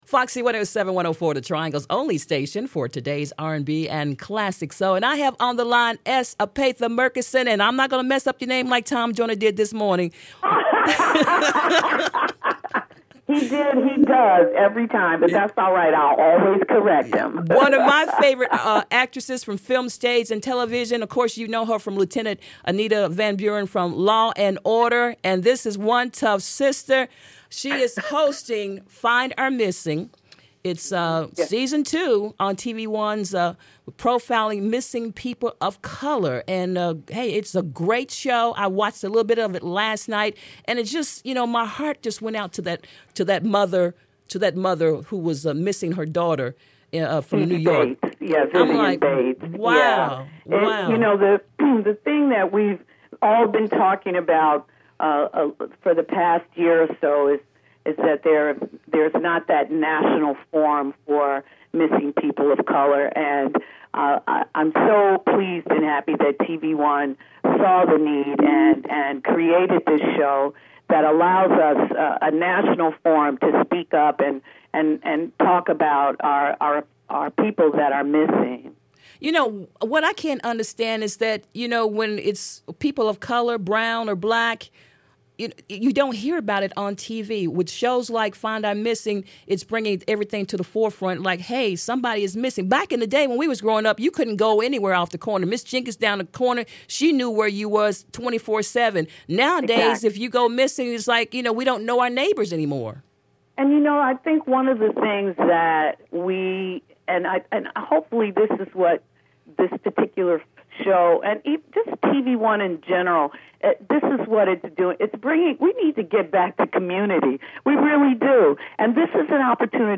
s-epatha-merkerson-interview.mp3